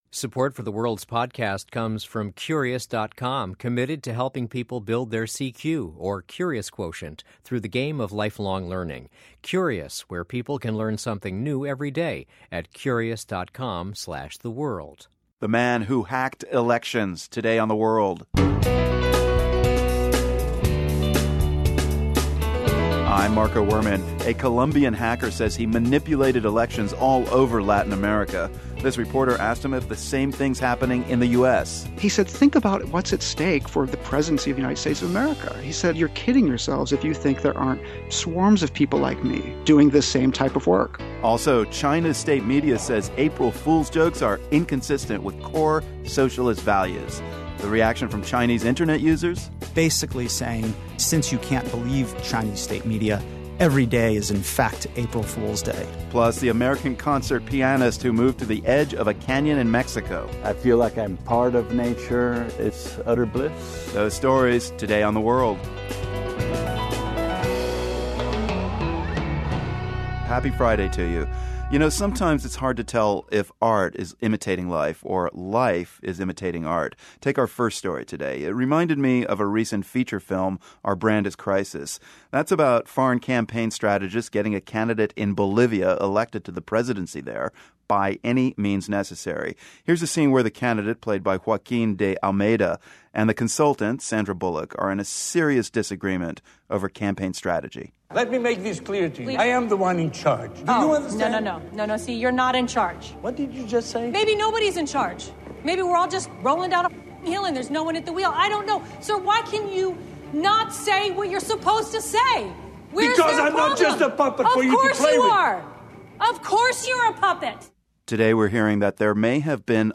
We talk to one man who says he's manipulated LOTS of elections in Latin America and you'll never guess who's his biggest client. We also hear how the easing of sanctions on Iran is affecting the supply of pistachios. Plus, we go to the edge of a canyon in northern Mexico to hear an American piano virtuoso play.